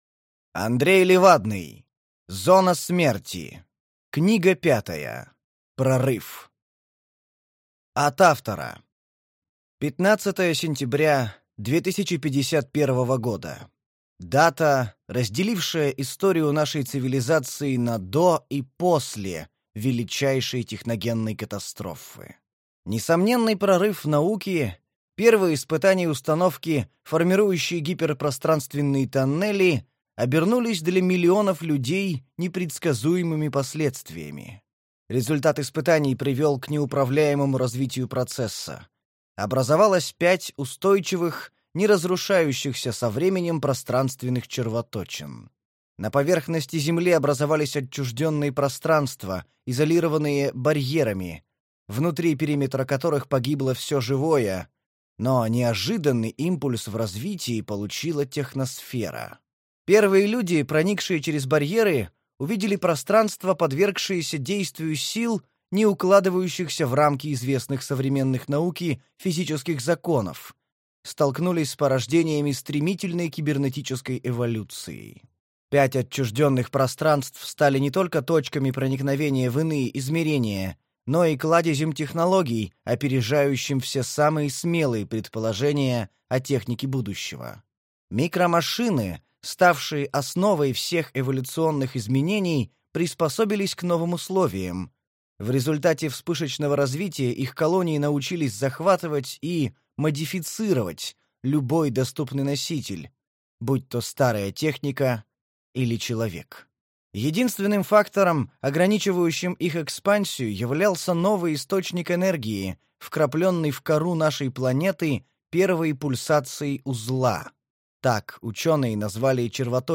Аудиокнига Прорыв | Библиотека аудиокниг